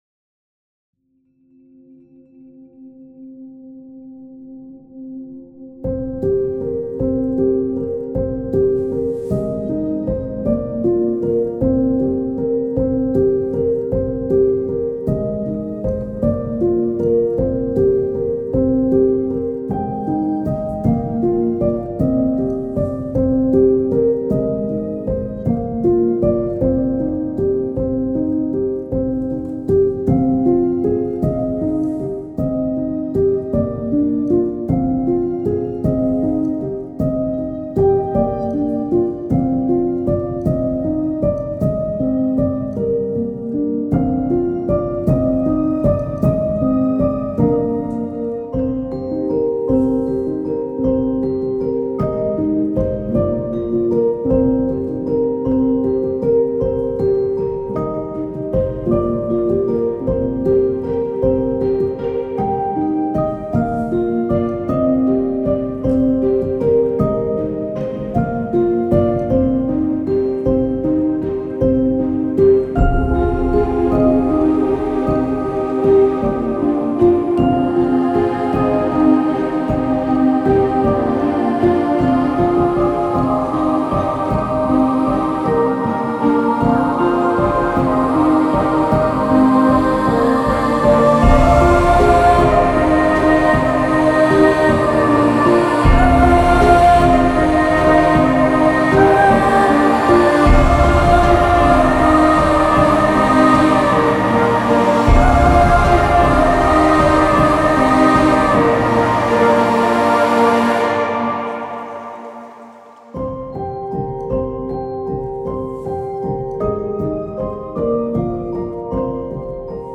آرامش بخش مدرن کلاسیک موسیقی بی کلام
موسیقی بی کلام احساسی